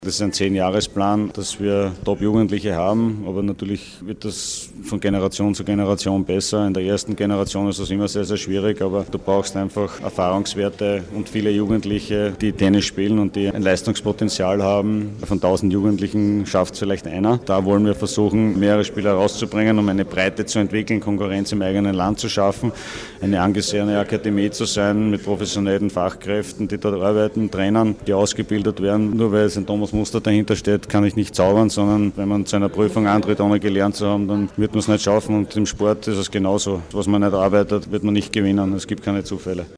Statements
Fragen an Thomas Muster: